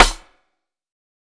TC2 Snare 18.wav